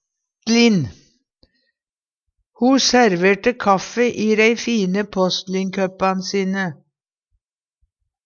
påsstlin - Numedalsmål (en-US)